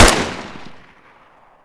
ump45_shoot3.ogg